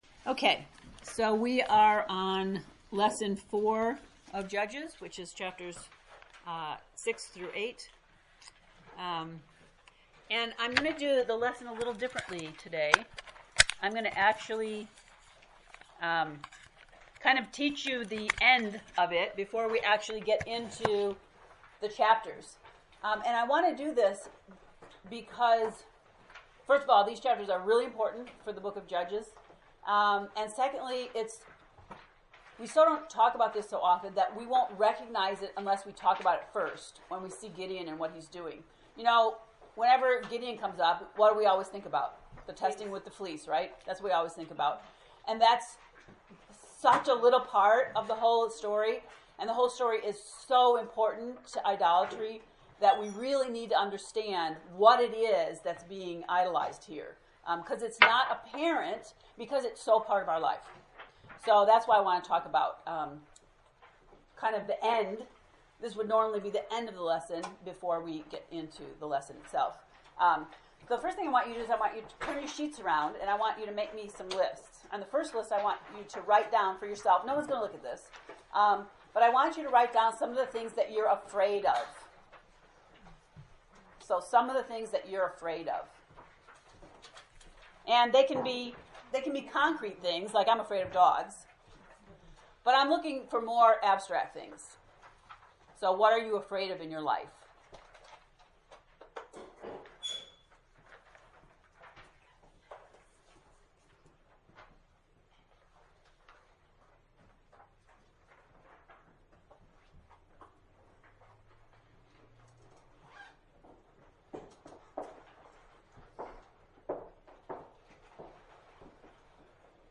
To listen to the lesson 4 lecture, “Is God Enough?” click below?